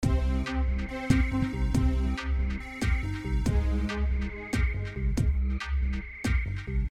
休息室节拍
描述：放松/悬疑的放松节拍。
Tag: 140 bpm Chill Out Loops Synth Loops 1.15 MB wav Key : Unknown